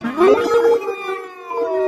Space sound 21
Tags: Science/Nature Sounds Recorded in Space Sci-Fi Skylab Sputnik Program